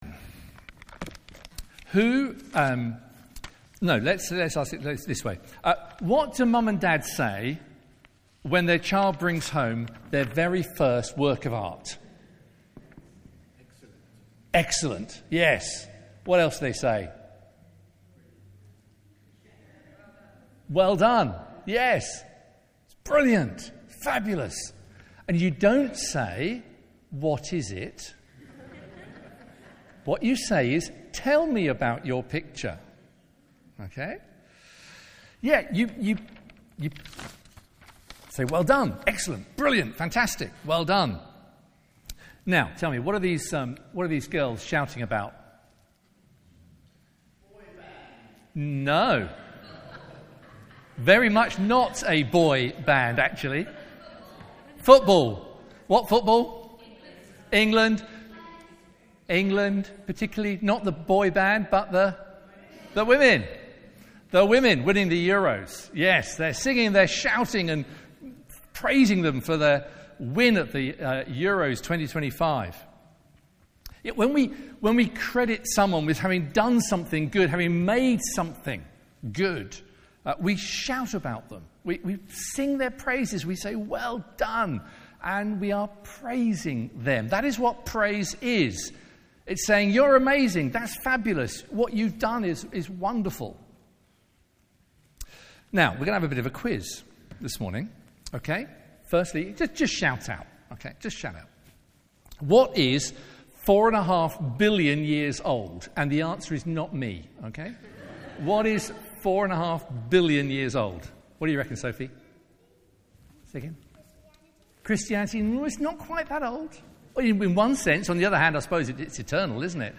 Media for Morning Service on Sun 03rd Aug 2025 10:30 Speaker
Psalms Theme: Sermon In the search box please enter the sermon you are looking for.